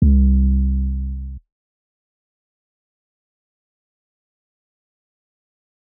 pbs - southside II [ 808 ].wav